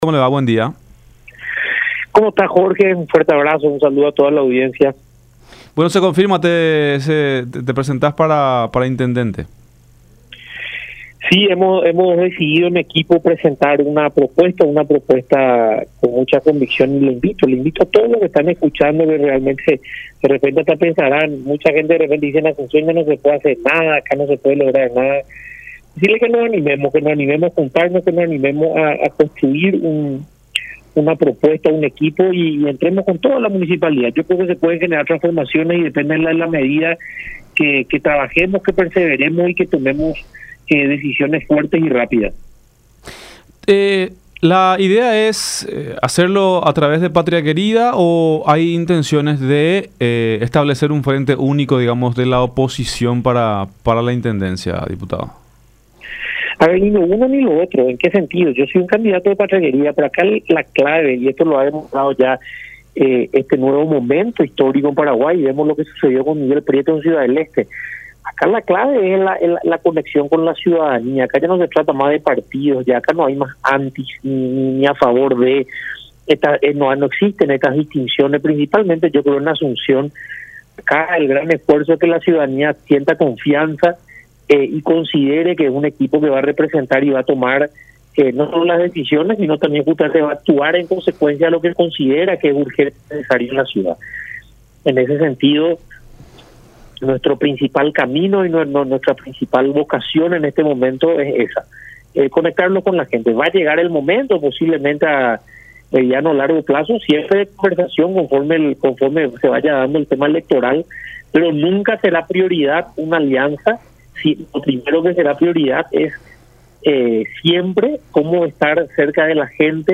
“Hemos decidido como equipo presentar una propuesta para intentar llegar a la intendencia de Asunción. Yo creo que se pueden generar transformaciones”, manifestó Villarejo en contacto con La Unión.